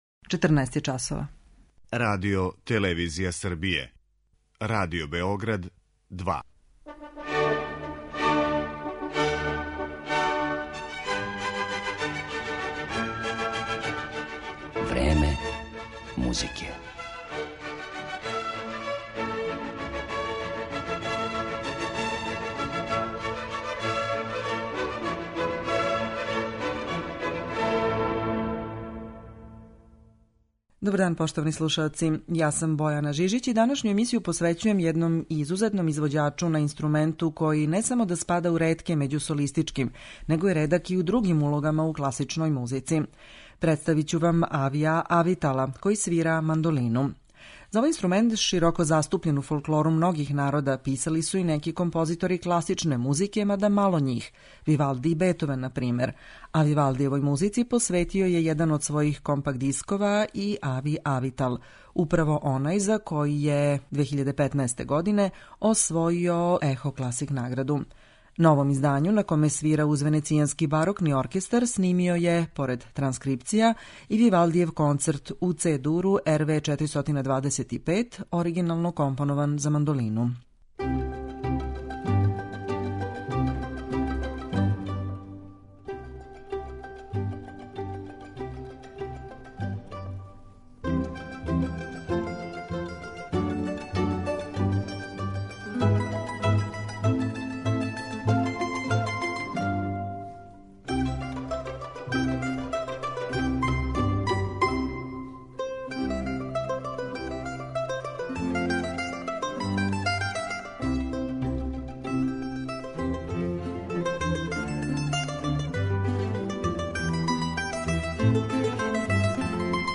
Данашња емисија посвећена је изузетном извођачу на инструменту који се не среће често у класичној музици ‒ на мандолини.